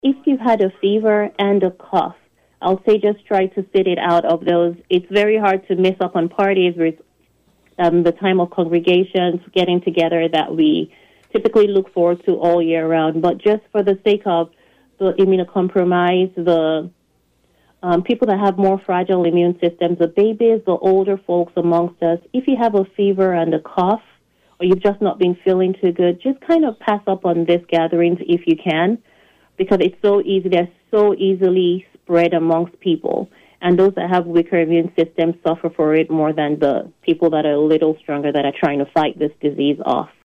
As we get deeper into the holiday and new year season, we are also quickly approaching the peak flu season, according to an illness trend update by Lyon County Health Officer Dr. Ladun Oyenuga on KVOE’s Morning Show Monday.